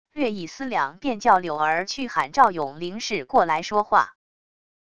略一思量便叫柳儿去喊赵勇凌氏过来说话wav音频生成系统WAV Audio Player